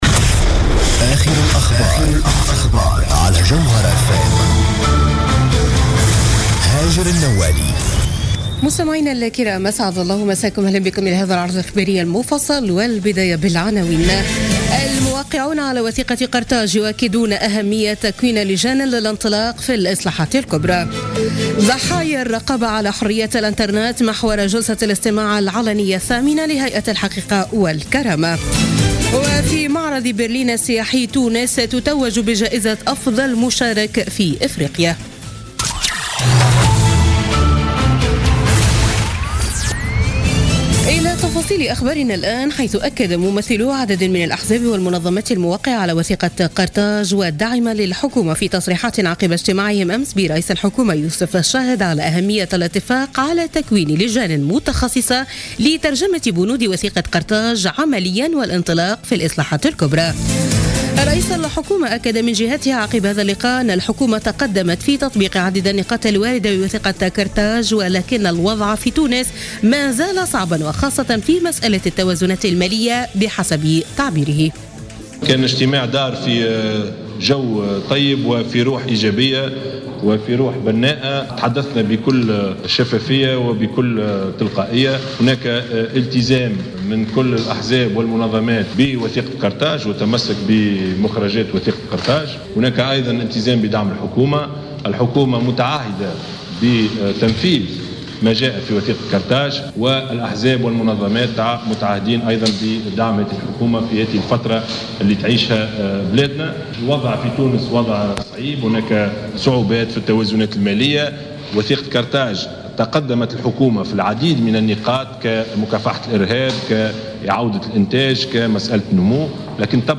نشرة أخبار منتصف الليل ليوم الأحد 12 مارس 2017